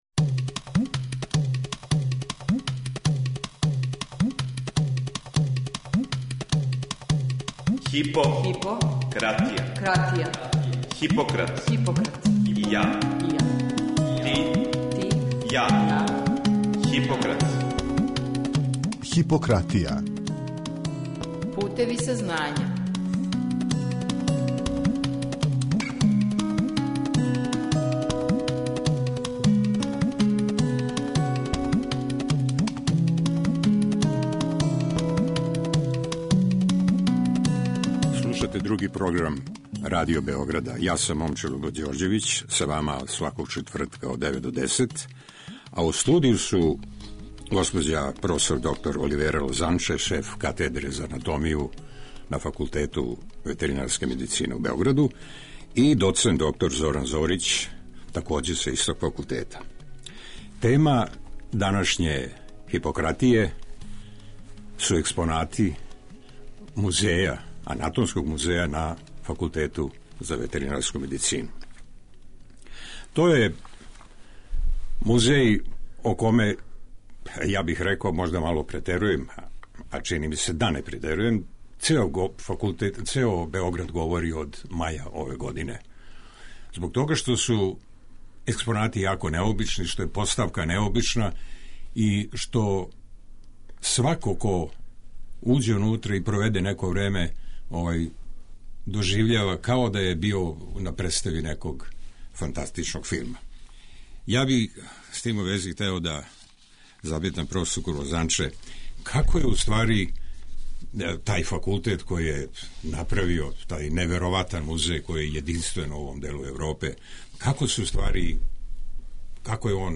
Гости у студију